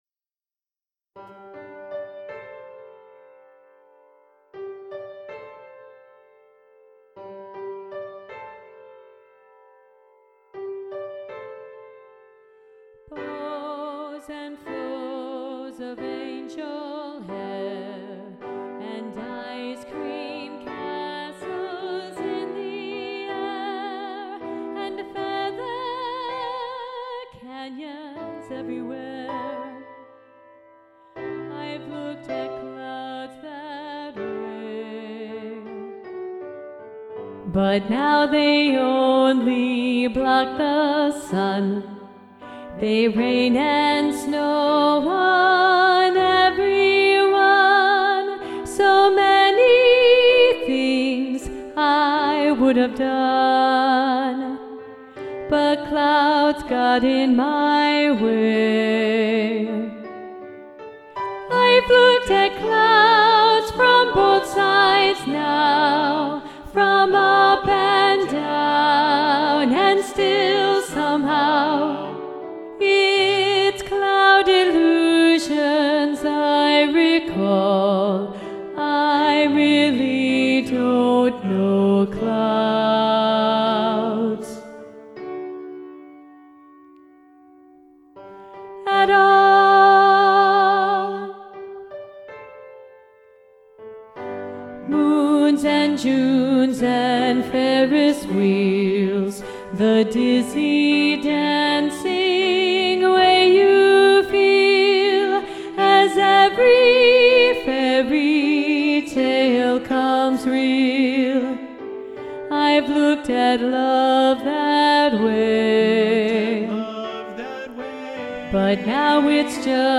Soprano 2 Predominant
Both-Sides-Now-SATB-Soprano-2-Predominant-arr.-Roger-Emerson.mp3